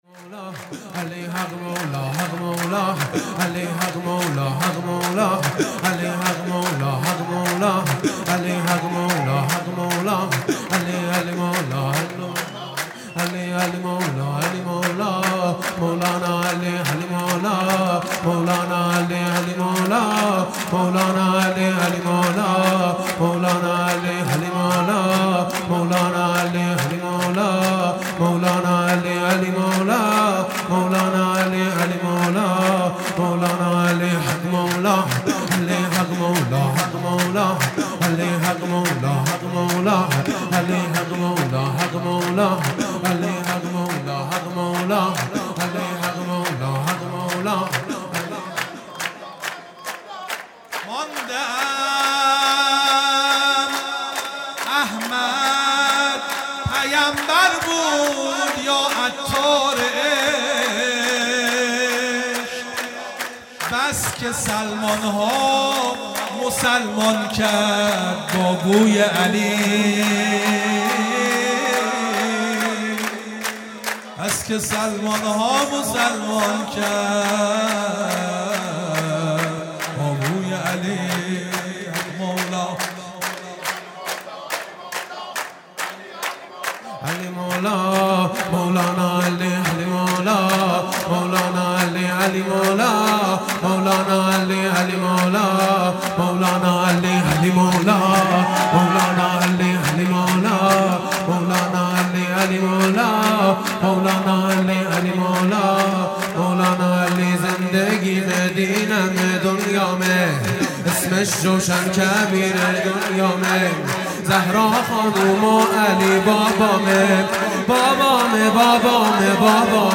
سرود
ولادت پیامبر اکرم (ص) | ۲۹ آبان ۱۳۹۷